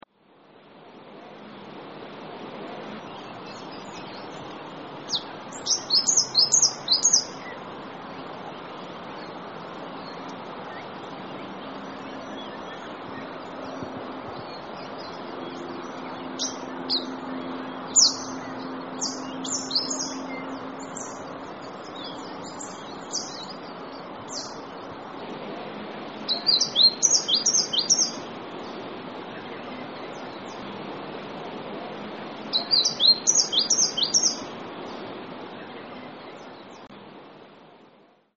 Mistletoebird - Dicaeum hirundinaceum
Voice: clear 'swizit, swizit', also mimicry.
Call 1: swizit call
Mistletoebird.mp3